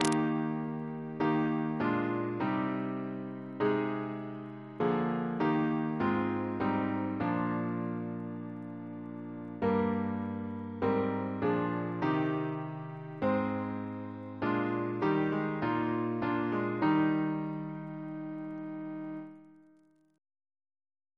Double chant in E♭ Composer: William Hayes (1707-1777) Reference psalters: PP/SNCB: 229